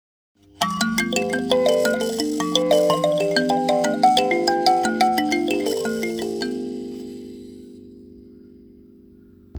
カリンバ M
親指でバーをはじくと優しい音色で心が和みます。
共鳴体は木・ひょうたん・空き缶などを使い、「ビリビリジャラジャラ」と心地よい癒しサウンドで人々を魅了します。
素材： 木 鉄 アルミ
こちらの商品はチューニングしてありません。